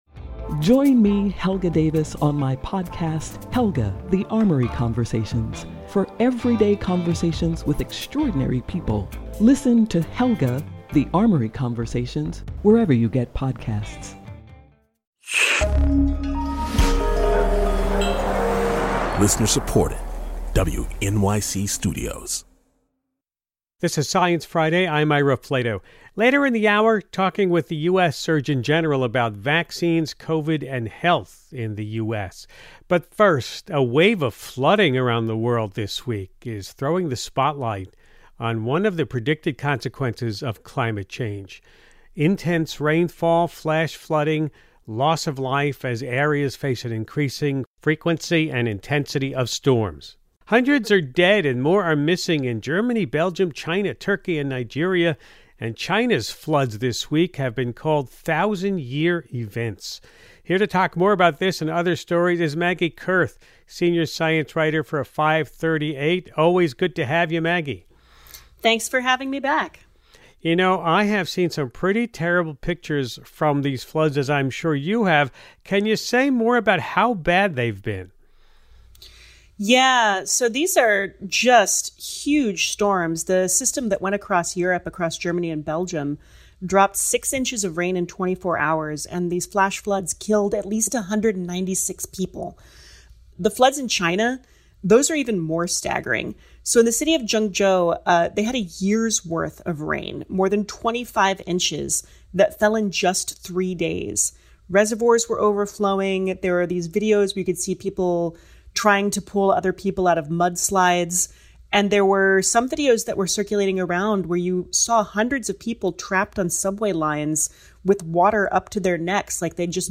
Dr. Vivek Murthy, Surgeon General of the United States, joins Ira to talk about vaccine hesitancy, the U.S. response to the pandemic, preparing for public health on a global scale, and post-pandemic public health priorities .